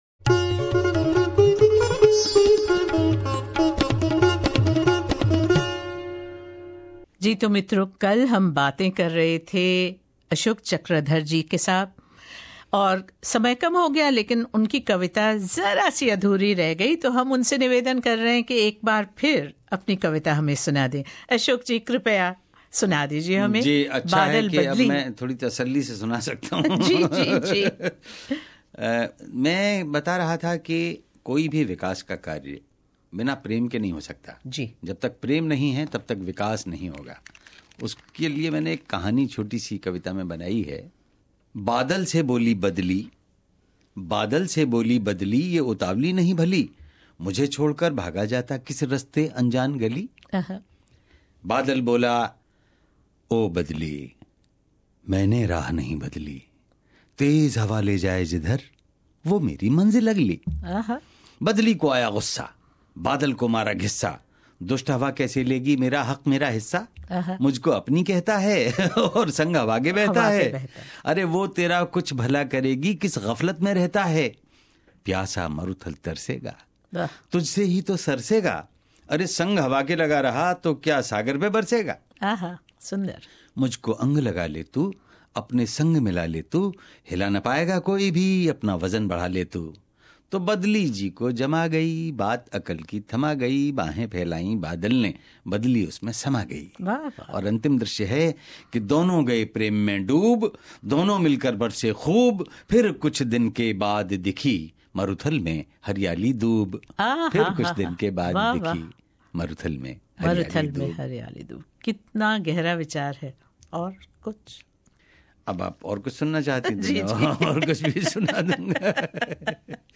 पद्मश्री अशोक चक्रधर सुना रहे हैं अपनी कविता बादल और बदली तथा कुछ अन्य कविताएं